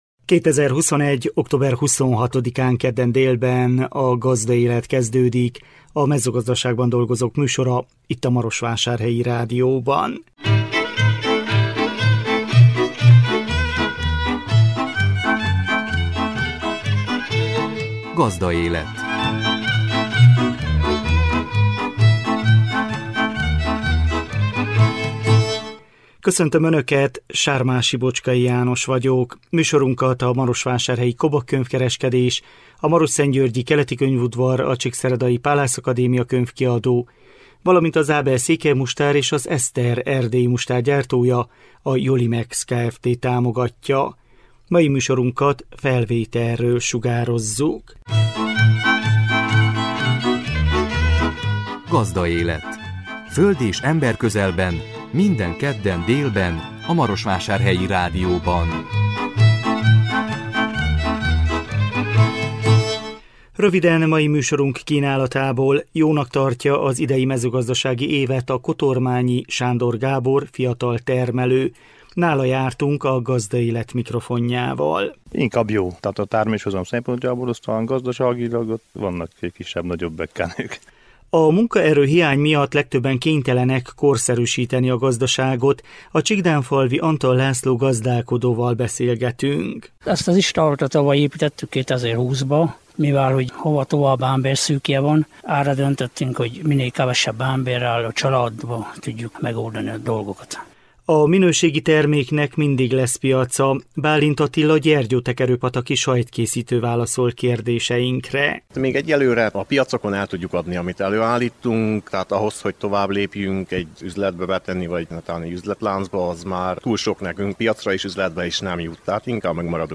Nála jártunk a Gazdaélet mikrofonjával. A munkaerőhiány miatt legtöbben kénytelenek korszerűsíteni a gazdaságot.